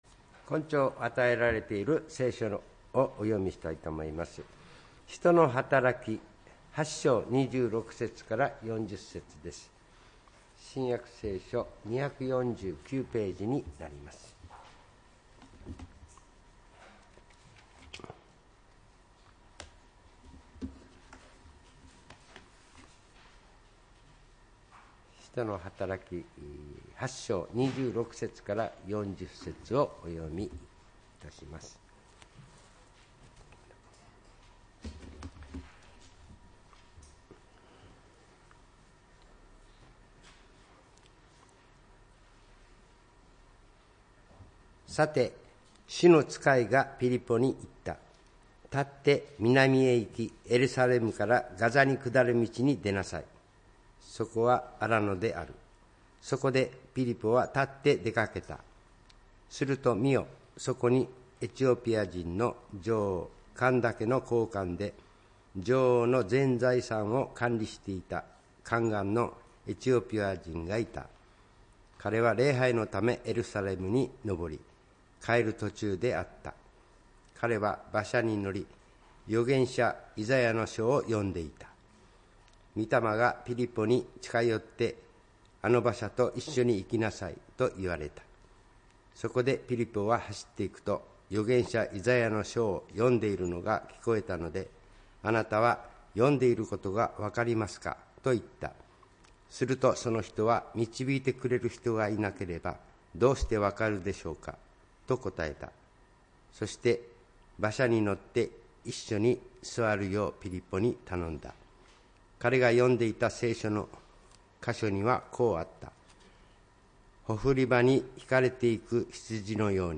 礼拝メッセージ「救いへの導き」(１月12日）